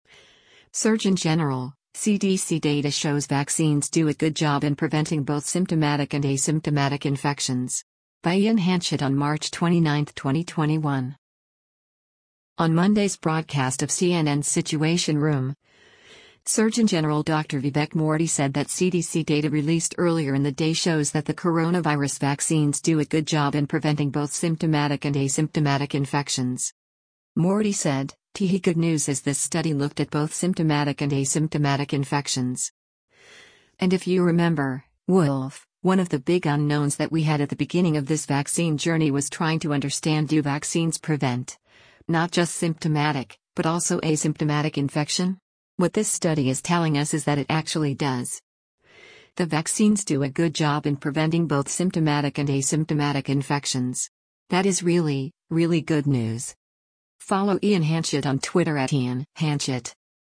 On Monday’s broadcast of CNN’s “Situation Room,” Surgeon General Dr. Vivek Murthy said that CDC data released earlier in the day shows that the coronavirus vaccines “do a good job in preventing both symptomatic and asymptomatic infections.”